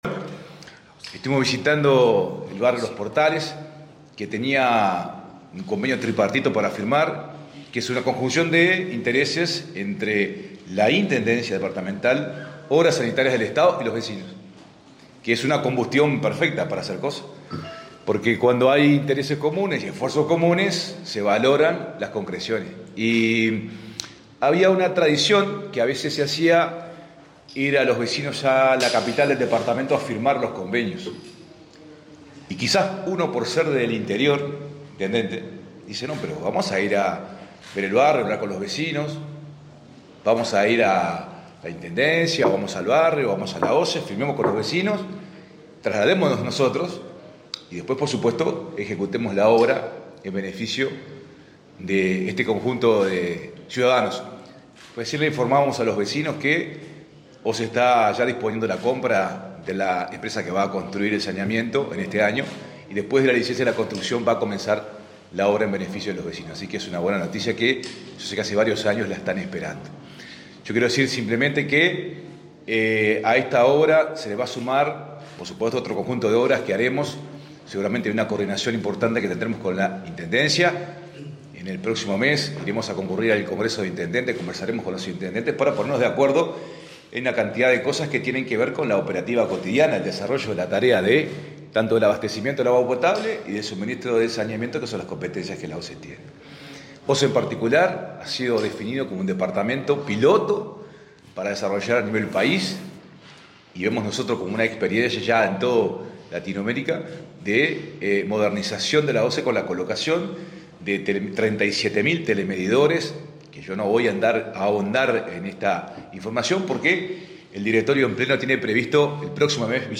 Declaraciones del vicepresidente de OSE, Guillermo Caraballo